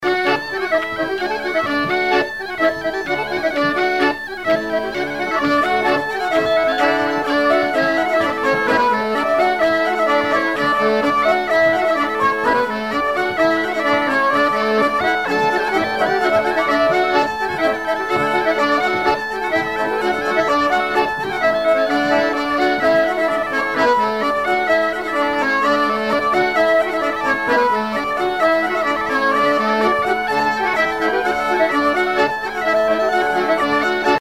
danse : branle : courante, maraîchine
bal traditionnel à la Minoterie, à Luçon
Pièce musicale inédite